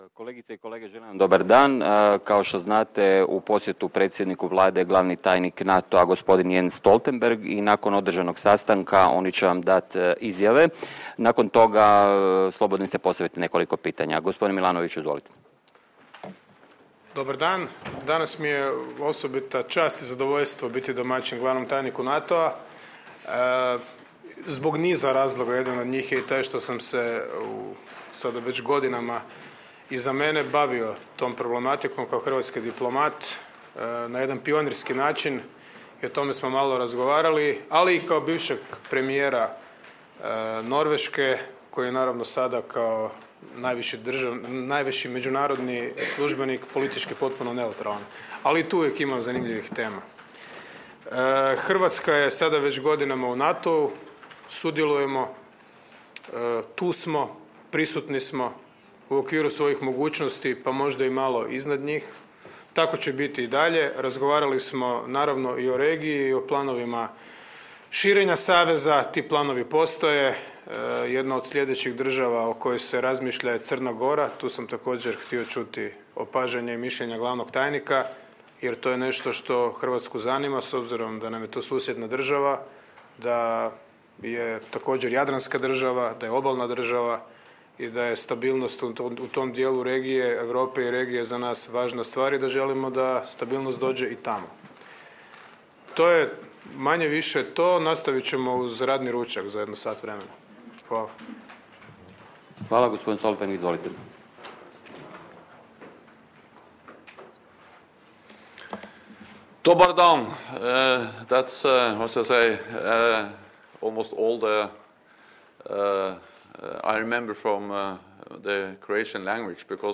Speech by NATO Secretary General Jens Stoltenberg at the opening session of the Croatia Forum 09 Jul. 2015 | download mp3 ORIGINAL - Joint press point with the Prime Minister of Croatia, Zoran Milanović and NATO Secretary General Jens Stoltenberg 09 Jul. 2015 | download mp3